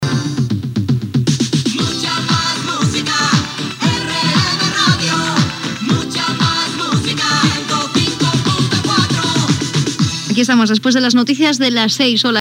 Indicatiu de l'emissora i hora